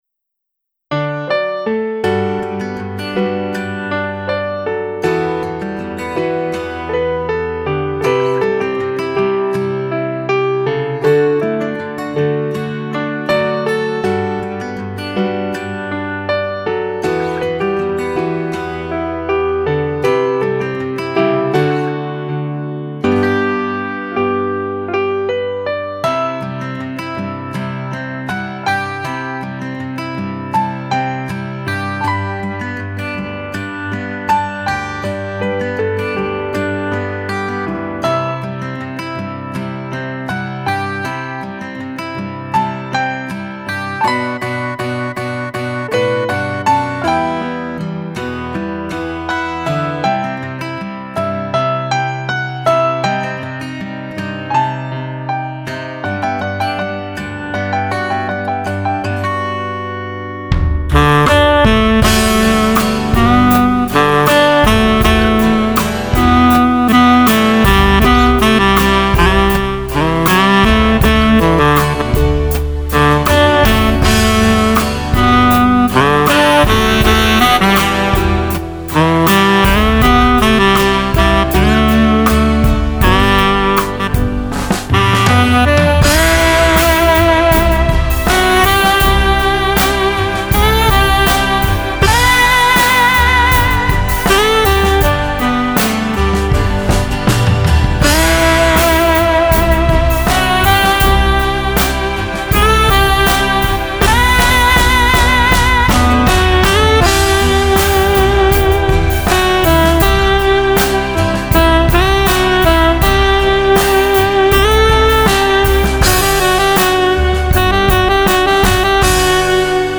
後、シンセストリングスパッドの隠し味に初音ミクを使用しています。
ピアノメロディ修正